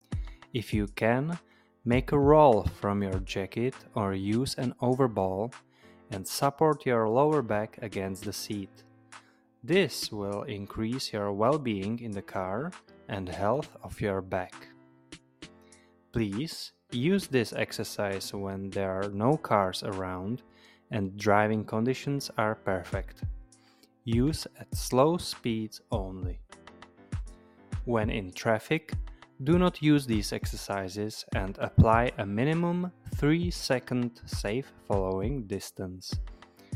Svůj klidný hlas mohu nahrát na profesionální mikrofon a poslat Vám text, který si zvolíte, v audioformátu, který si vyberete.
Klidný mužský hlas